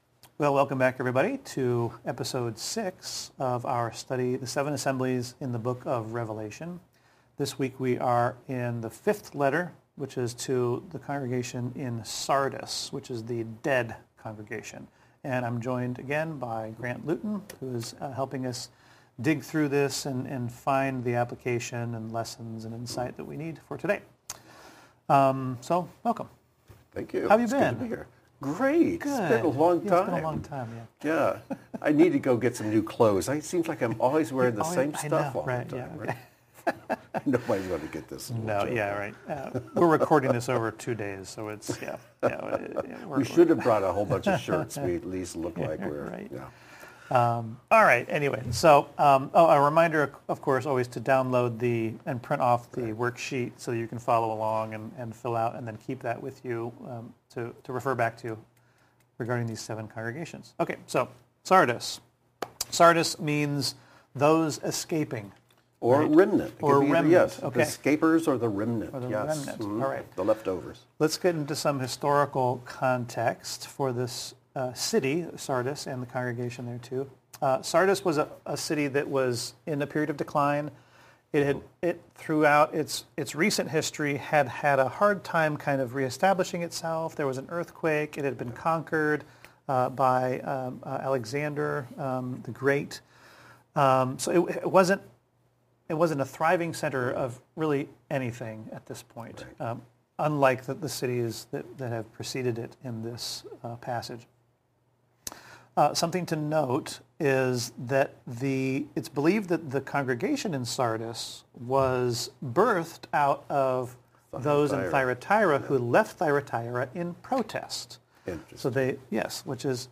they have a conversation about the Seven Assemblies of Revelation chapters 2 and 3. For each assembly, they touch upon historical background, application to the individual Believer and various types of fellowships, phases of church history, and alignment with one of the seven Kingdom Parables in Matthew 13. This week they explore Yeshua’s fifth letter, written for the Believers at Sardis.